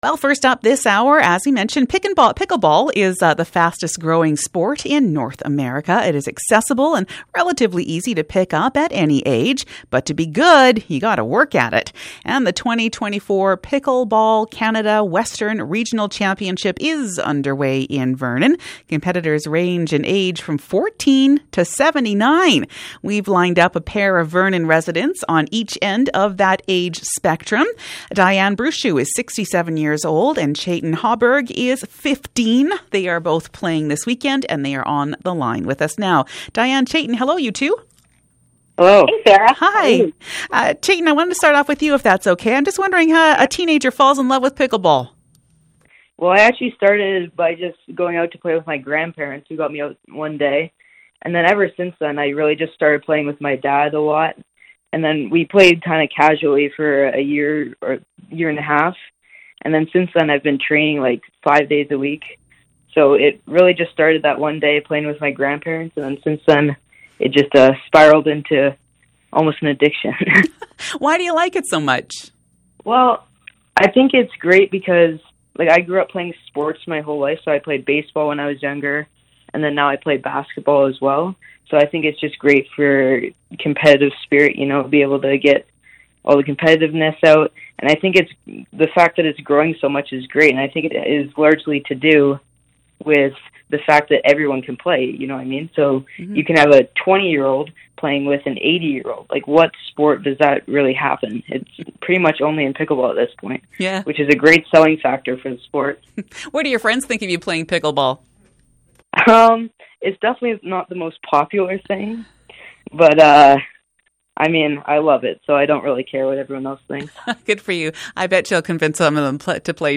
CBC Interview – Radio one: VPA MP3 Interview